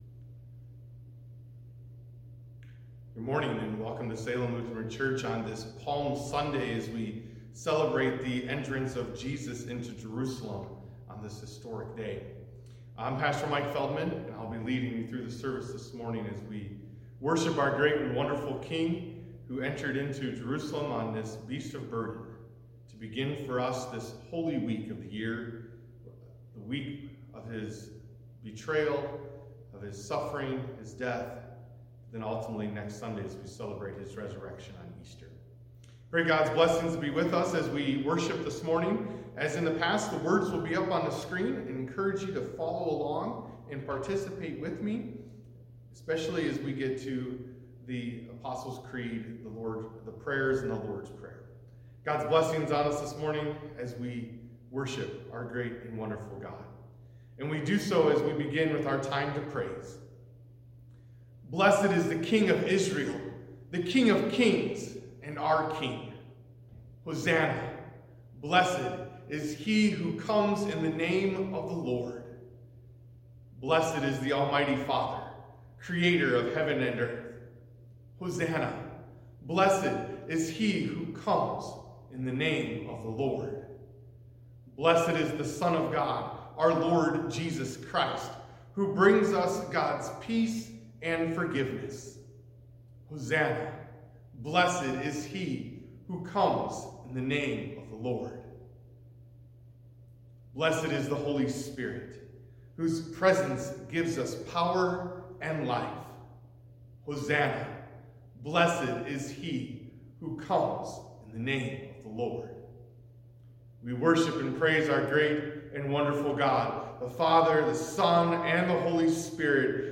April-5-2020-Sunday-Service.mp3